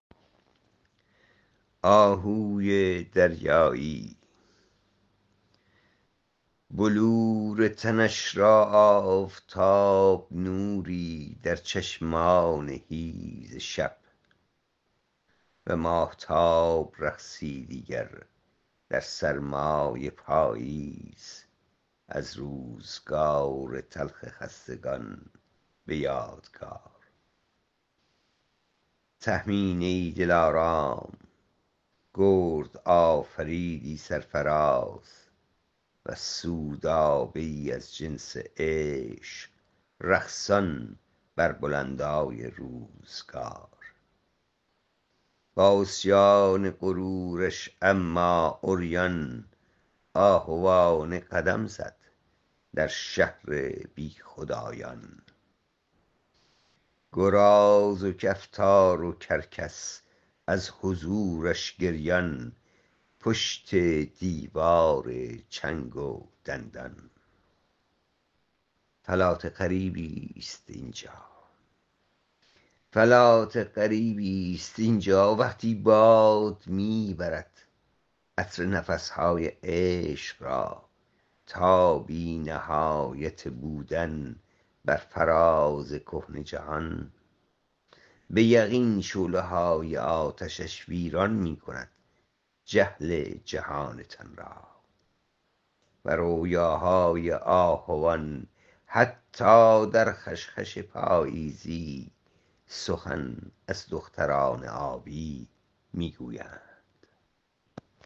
این شعر را با صدای شاعر بشنوید